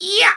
Super Mario Eeeyah Sound Effect Free Download